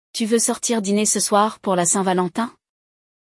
Neste episódio, você vai acompanhar a conversa de um casal que está planejando o que fazer nesta data tão especial.
O episódio traz explicações em português para garantir que todos compreendam o conteúdo e consigam acompanhar a conversa.